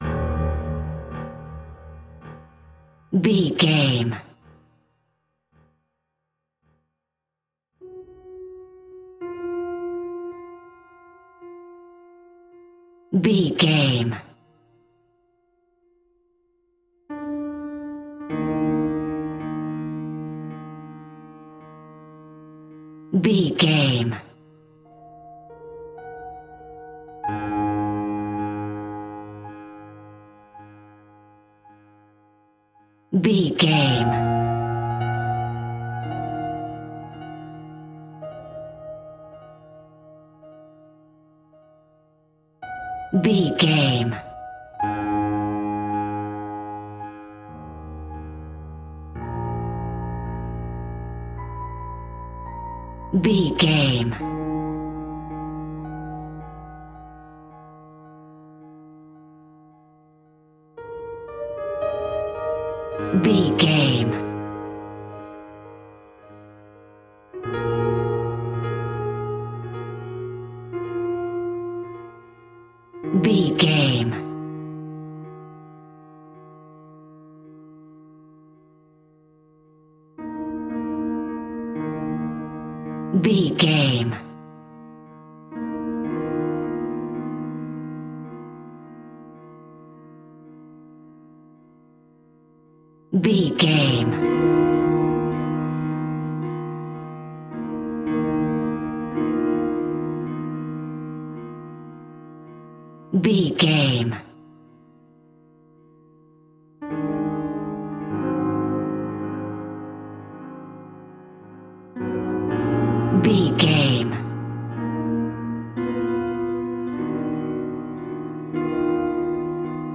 Aeolian/Minor
E♭
scary
ominous
dark
suspense
haunting
eerie
creepy